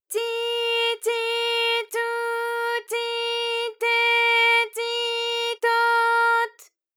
ALYS-DB-001-JPN - First Japanese UTAU vocal library of ALYS.
ti_ti_tu_ti_te_ti_to_t.wav